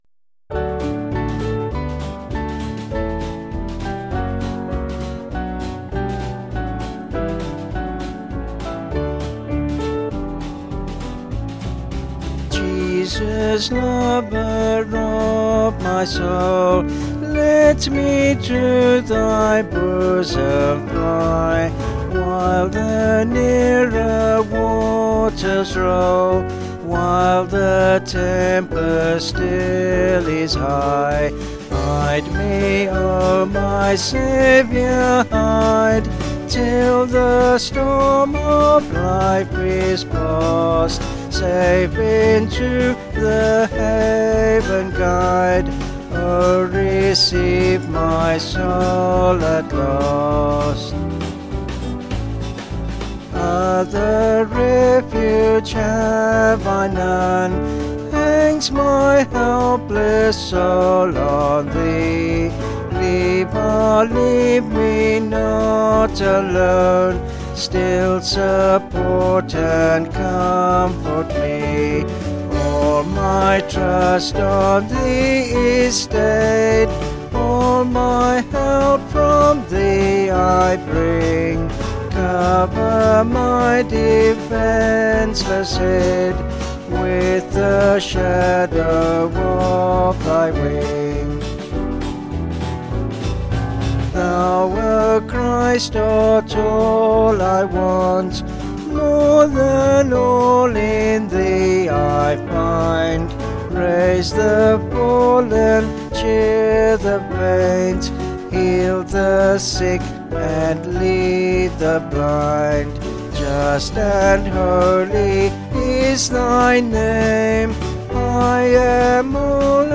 Vocals and Band   264.8kb Sung Lyrics